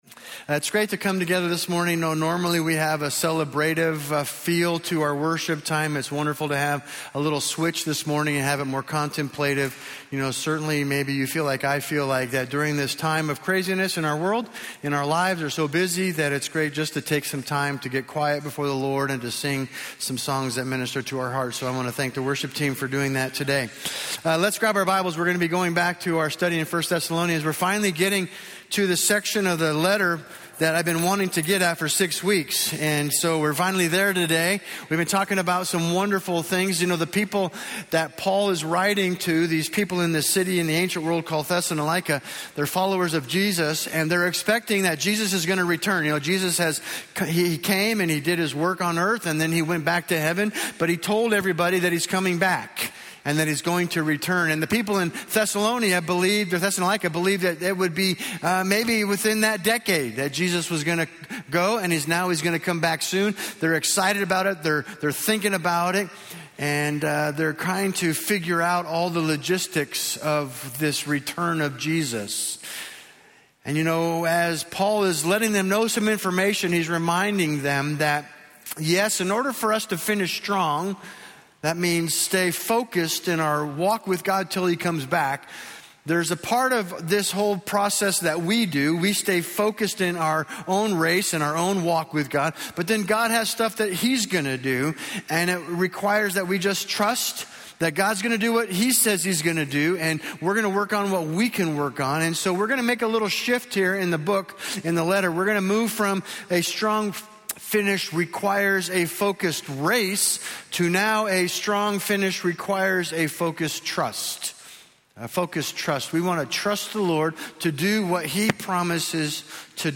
A message from the series "Finish Strong."